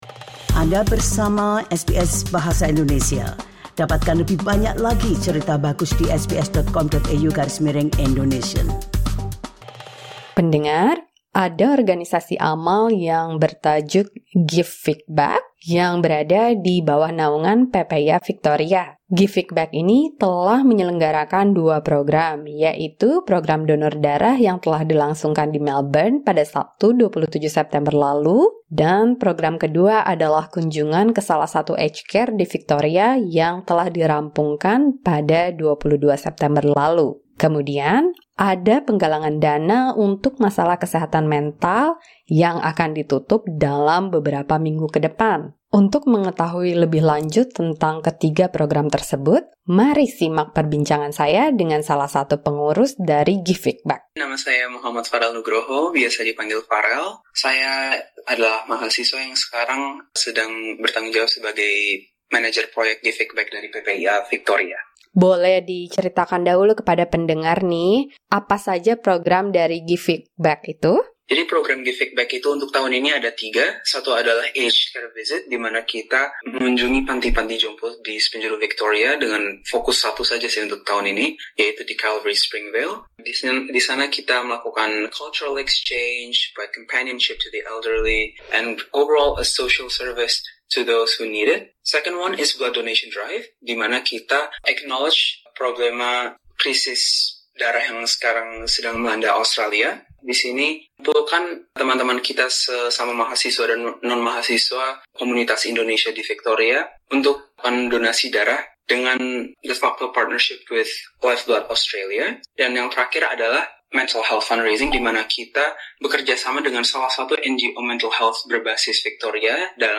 SBS Indonesian berbincang dengan